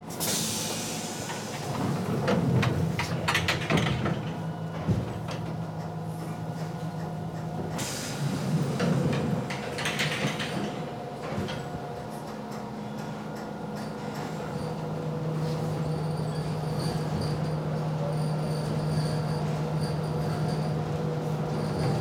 Звук захлопывающихся дверей в метро